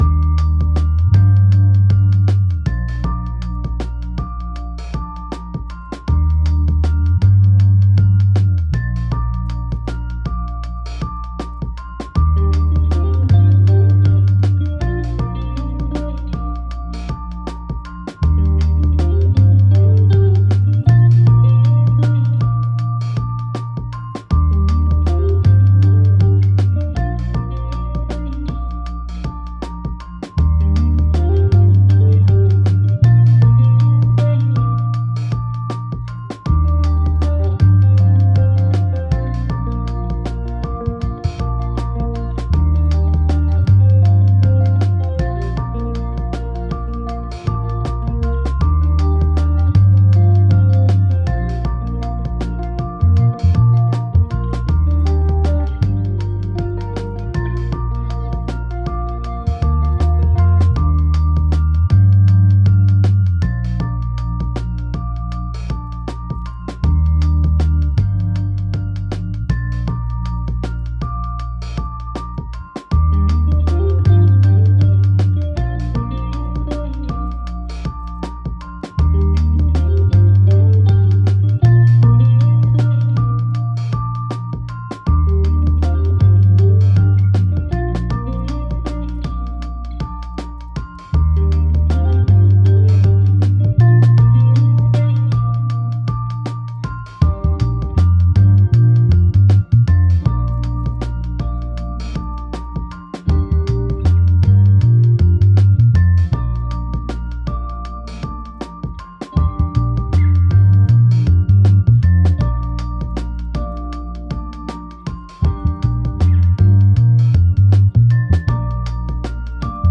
79 bpm – Relaxed Lofi Hip-Hop
79-bpm-Relaxed-Lofi-Hip-Hop.wav